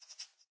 minecraft / sounds / mob / rabbit / idle2.ogg